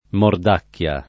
[ mord # kk L a ]